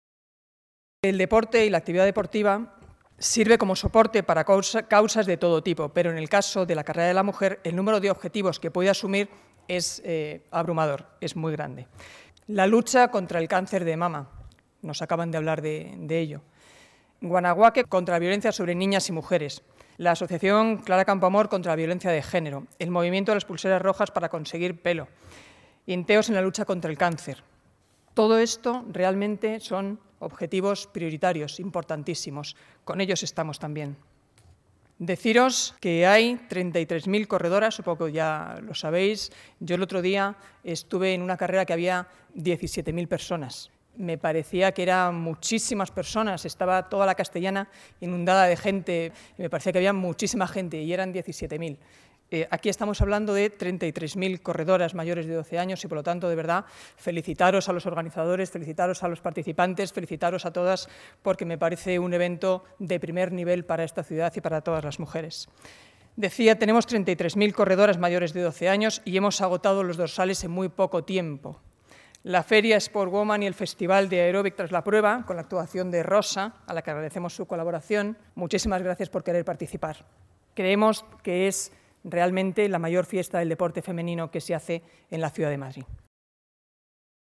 Marta Higueras presenta la XV Carrera de la Mujer